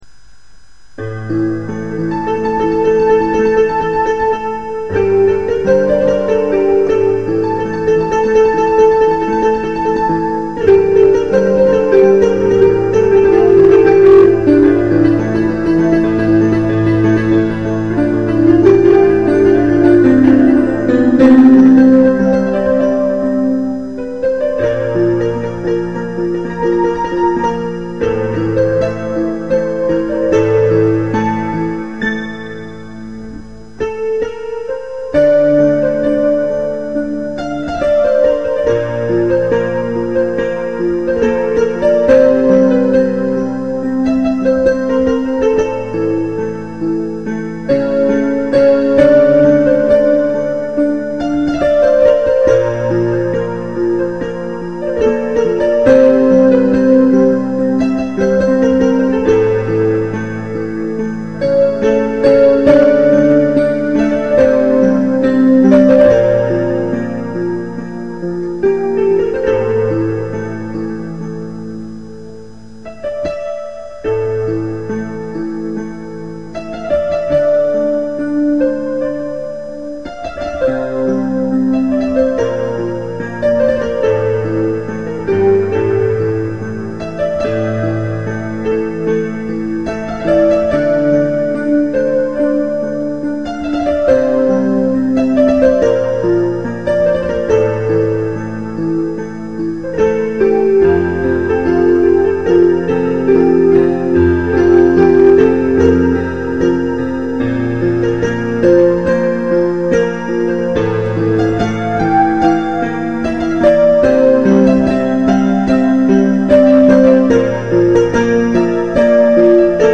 sadece piano